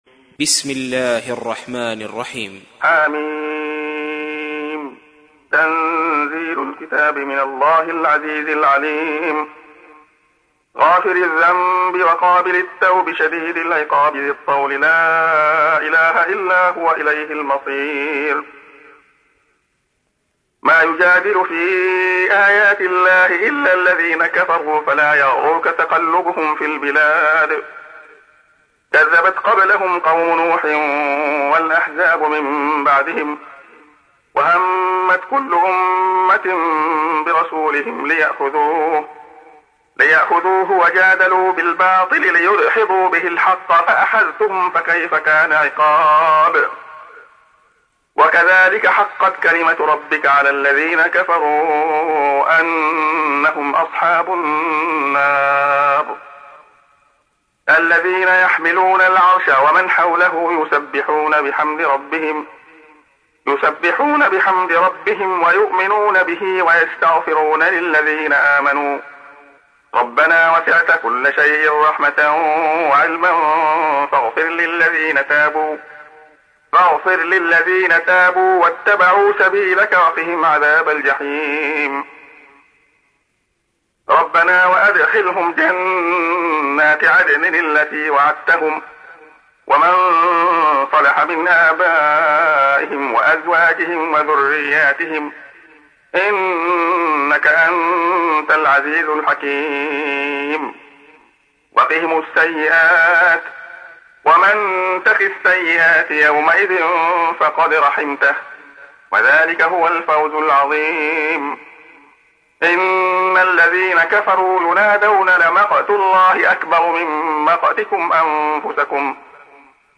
تحميل : 40. سورة غافر / القارئ عبد الله خياط / القرآن الكريم / موقع يا حسين